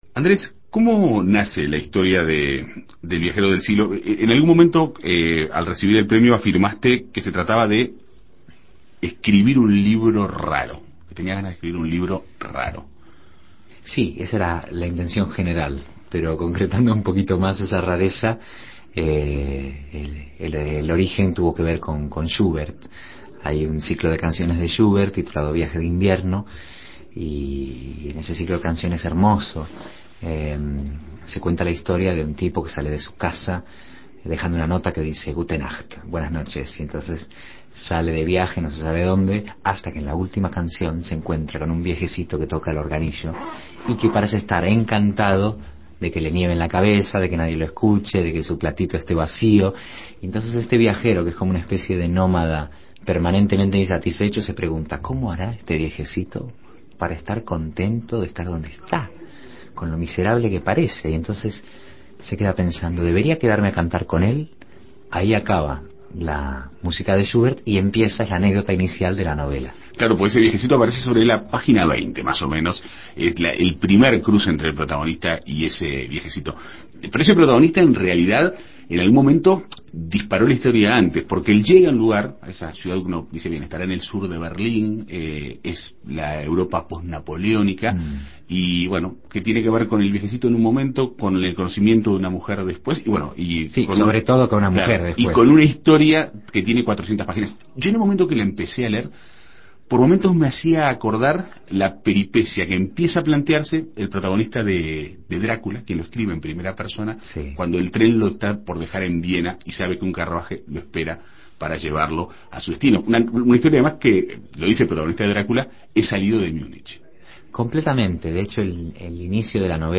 El autor dialogó sobre esta historia de amor en la Segunda Mañana.